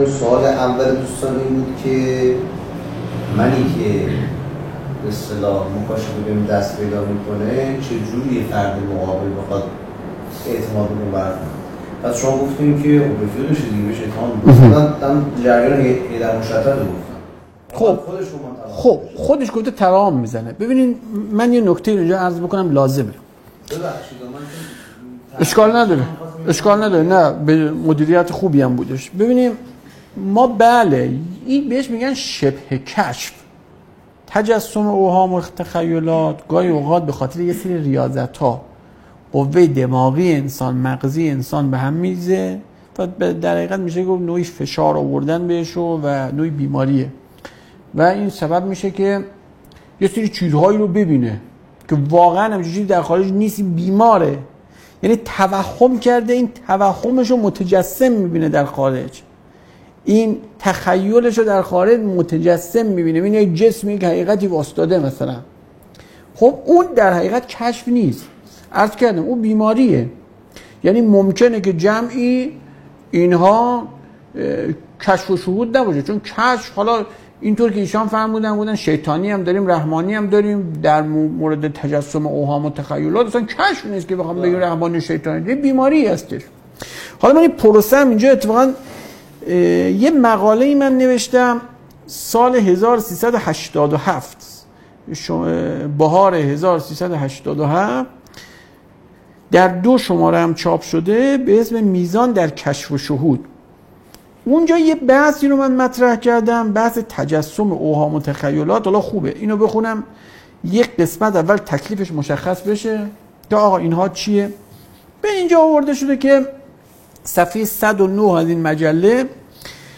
صوت ســـخنرانی: